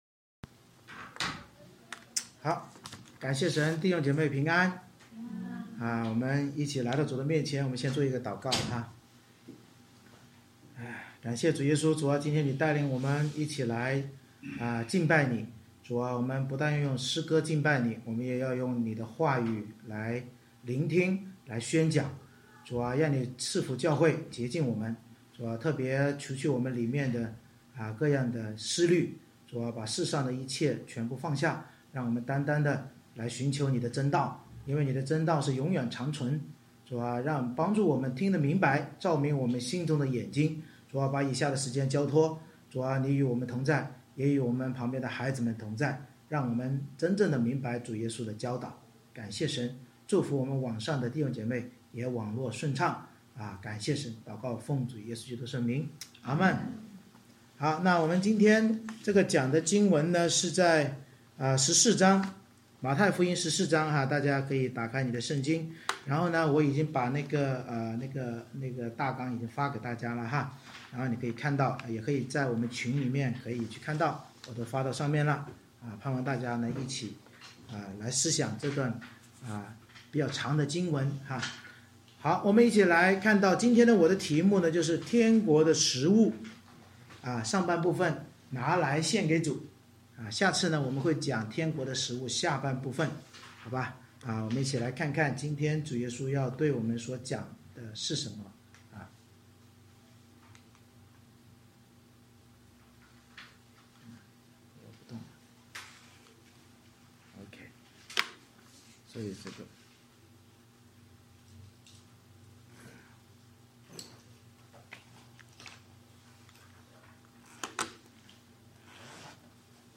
马太福音14章 Service Type: 主日崇拜 面对魔鬼仇敌的凶残和人生风浪的威胁，我们要将自己一切交托主耶稣，祂就能藉着我们受苦和信靠的「五饼二鱼」之生命转化为万人祝福。